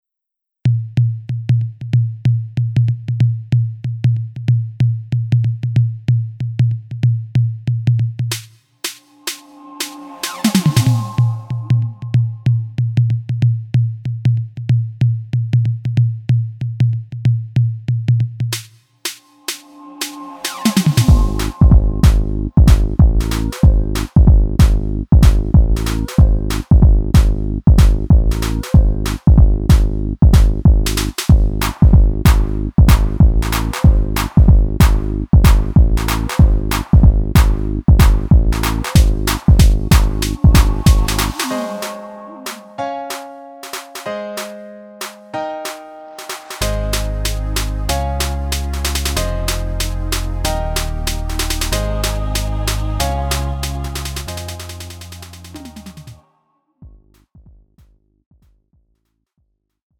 음정 원키 2:49
장르 가요 구분 Lite MR